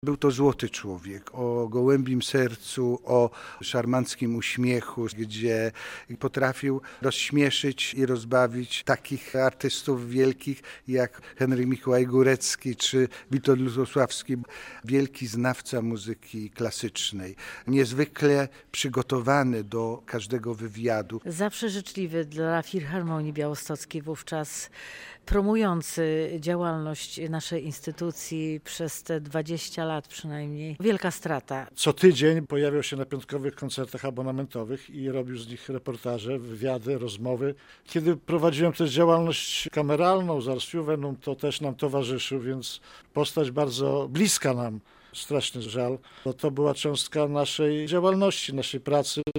Muzycy wspominają